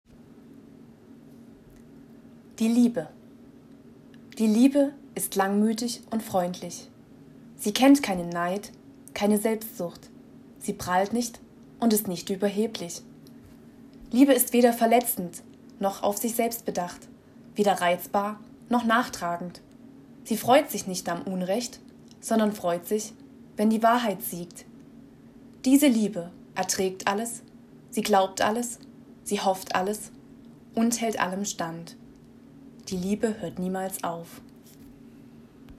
Meine Reden gestalte ich in deutsch oder englisch – selbstverständlich akzentfrei. Hörprobe deutsch: